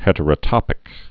(hĕtər-ə-tŏpĭk)